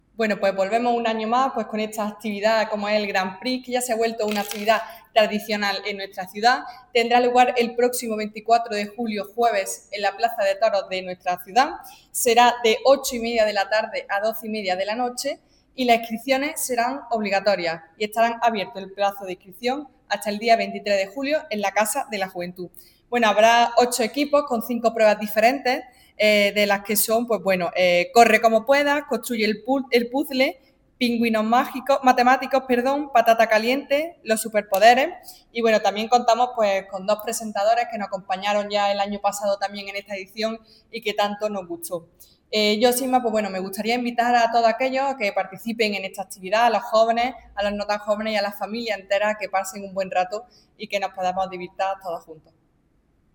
La concejal de Juventud, Marta González, informa del próximo desarrollo de una nueva edición del Grand Prix, destacando su consolidación como una de las citas más participativas del verano juvenil antequerano.
Cortes de voz